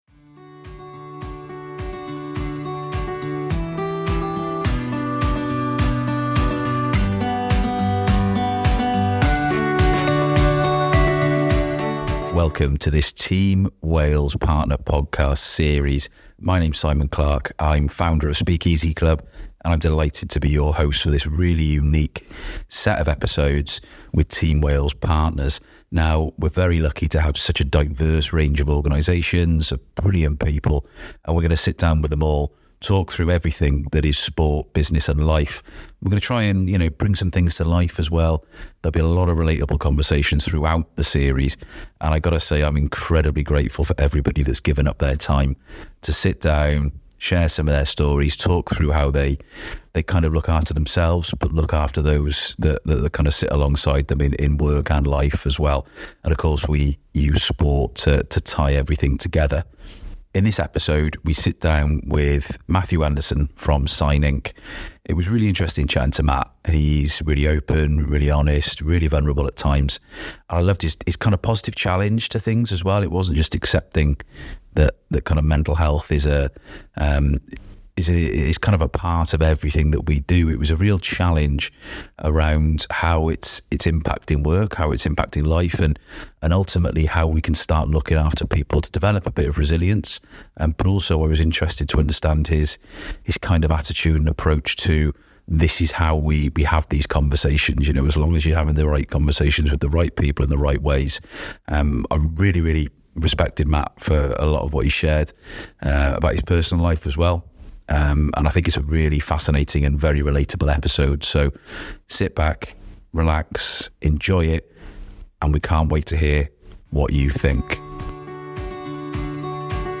In this series, we’re joined by Team Wales partners and some very special guests for an open and honest conversation about mental health in business, sport and life. We’ll explore how these organizations support their teams, the reasons behind their commitment to mental well-being, and how to navigate those tough but essential conversations.